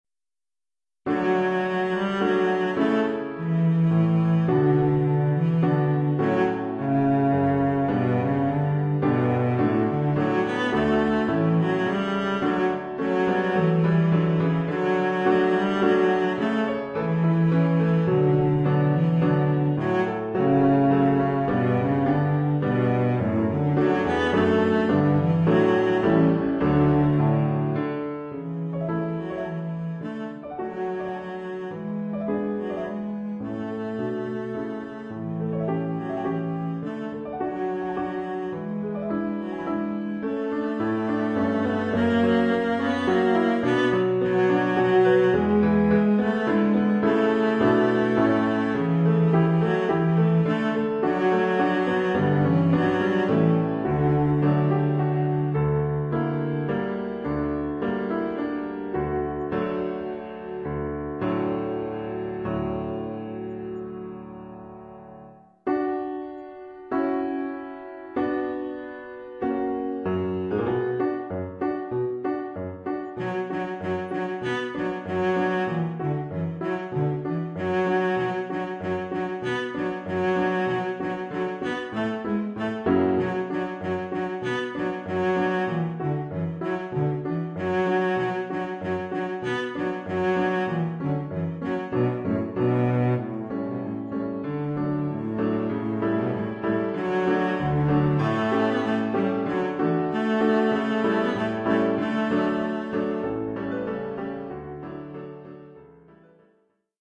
Oeuvre pour violoncelle et piano.